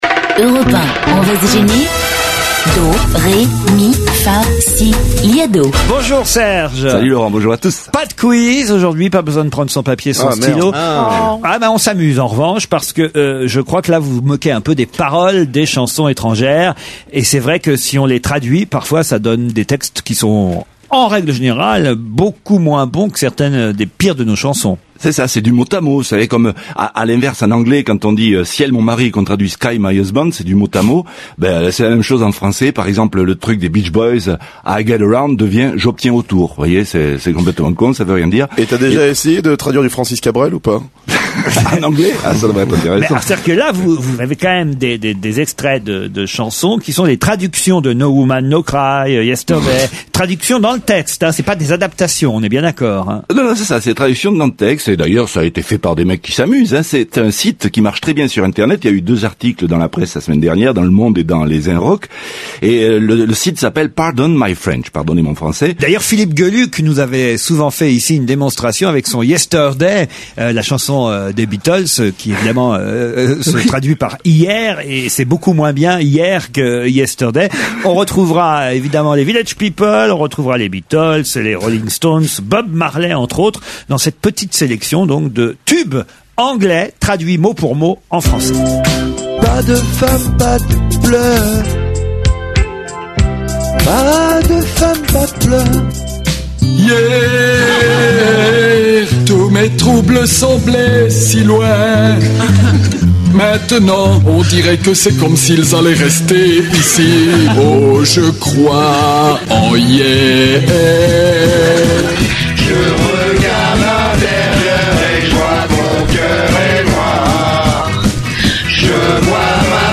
Et bah non, il en a pris aucun, il y a casé des trucs qui sont pas sur l'site, les participants sont tous des "anonymes", bref la grande classe...